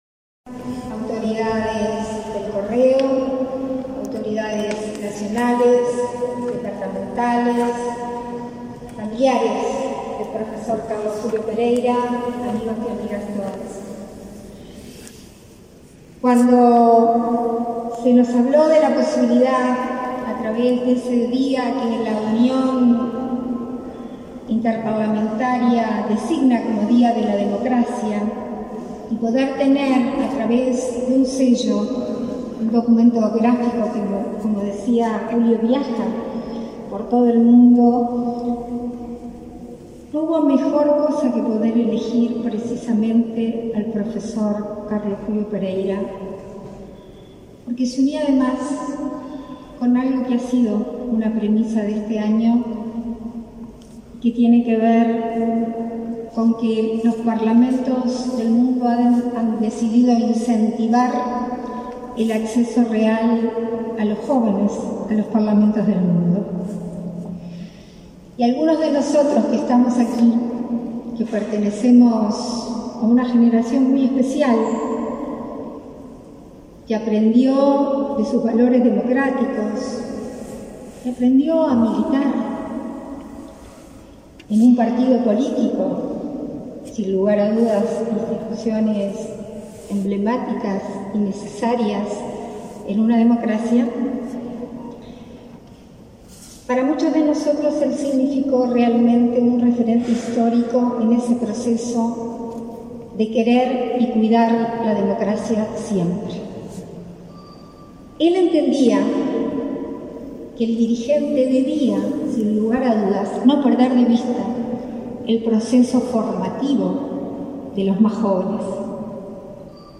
Palabras de la vicepresidenta de la República, Beatriz Argimón
Palabras de la vicepresidenta de la República, Beatriz Argimón 15/11/2021 Compartir Facebook X Copiar enlace WhatsApp LinkedIn El presidente de la República, Luis Lacalle Pou, participó en el lanzamiento oficial del sello conmemorativo de la serie Día de la Democracia en homenaje a Carlos Julio Pereyra, en el Salón de los Pasos Perdidos del Palacio Legislativo. En el acto, se expresó la vicepresidenta, Beatriz Argimón.